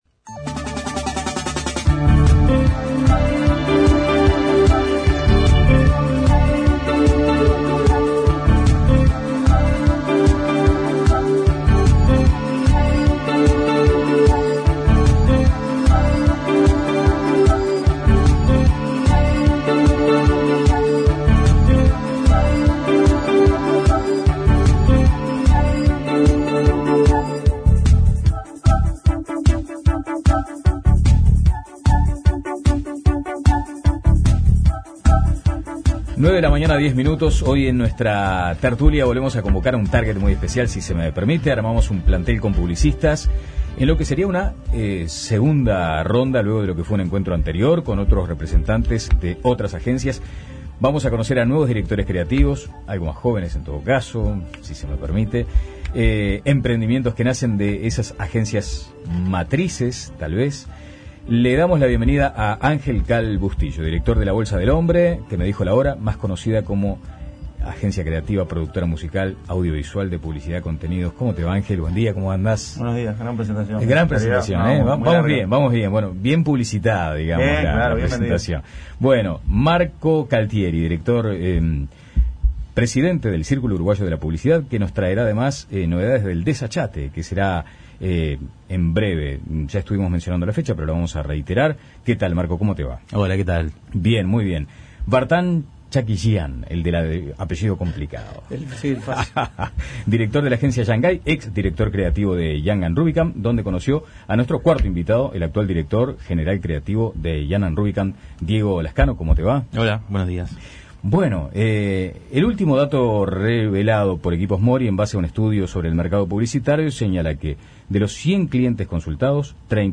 La tertulia estuvo integrada por un plantel de publicistas, en lo que sería una segunda ronda luego de lo que fue el encuentro anterior con los representantes de alguna de las agencias con mayor posicionamiento.